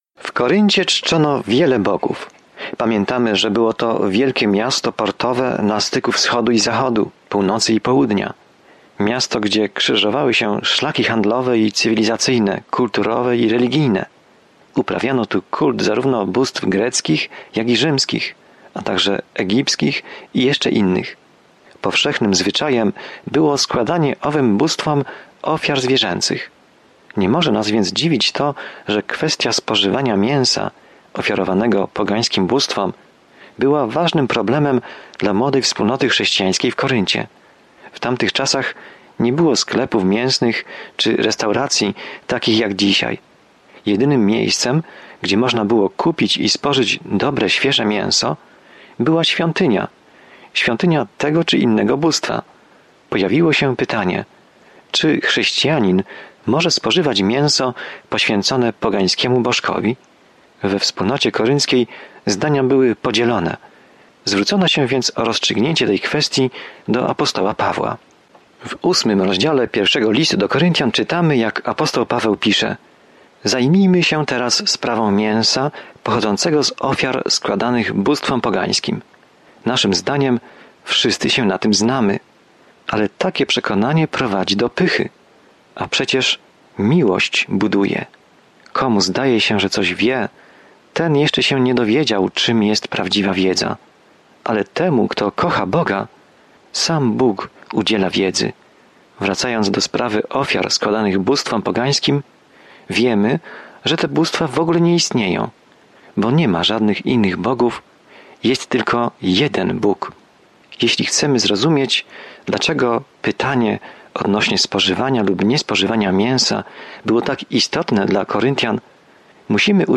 Jest to temat poruszony w Pierwszym Liście do Koryntian, zawierający praktyczną opiekę i korektę problemów, przed którymi stają młodzi chrześcijanie. Codziennie podróżuj przez 1 List do Koryntian, słuchając studium audio i czytając wybrane wersety słowa Bożego.